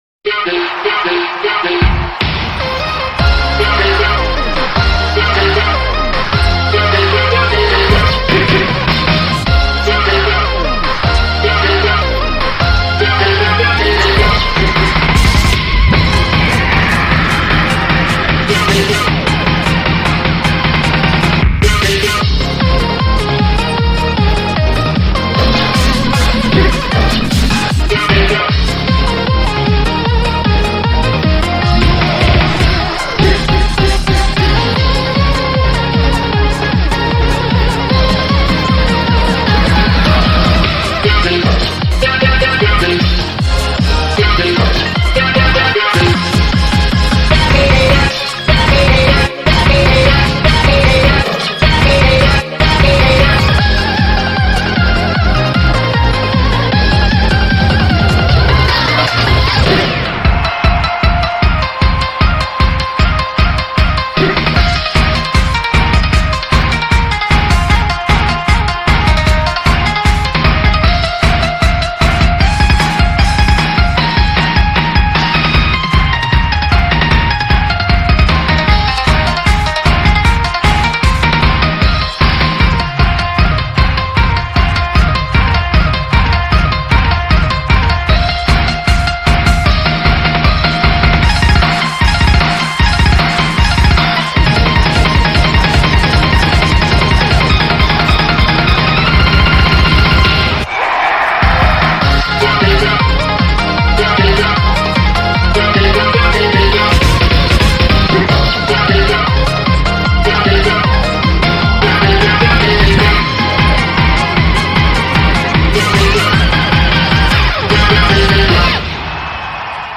BPM153
Audio QualityPerfect (High Quality)
Song type: Game remix